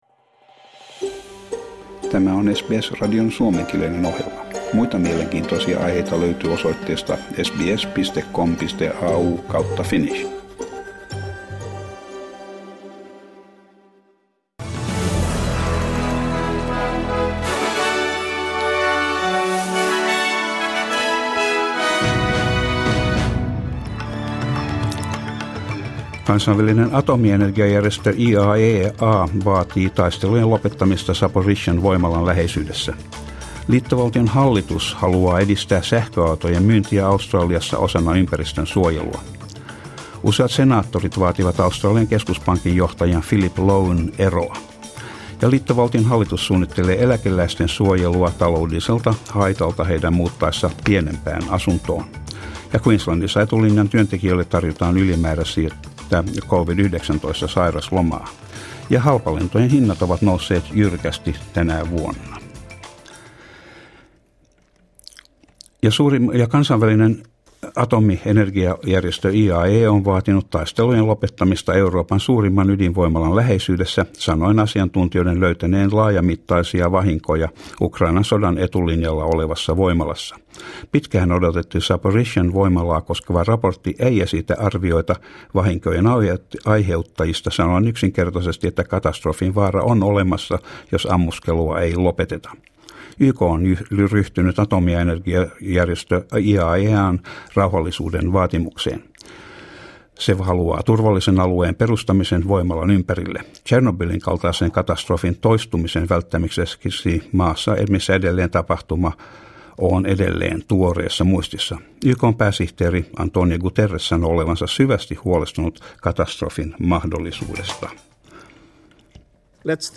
Uutiset ja sää 7.9.22
Suomenkieliset uutiset Source: SBS